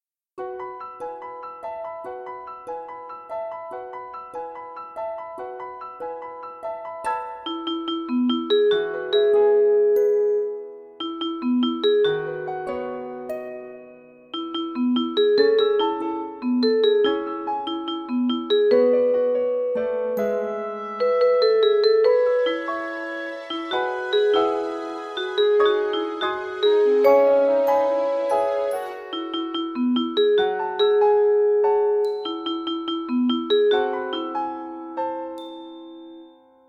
Recueil pour Violon